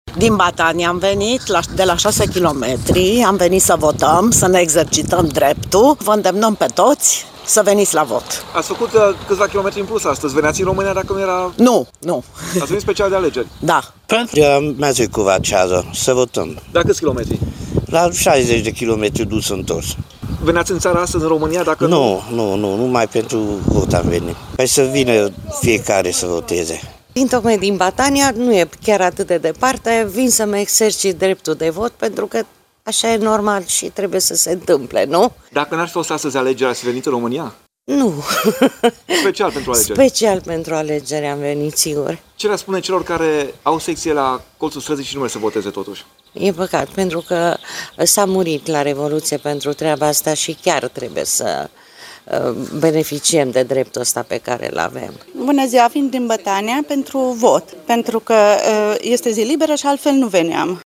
O parte din alegători spun că au venit special pentru alegeri în România